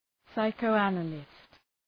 Προφορά
{,saıkəʋ’ænəlıst}